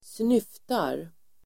Ladda ner uttalet
Uttal: [²sn'yf:tar]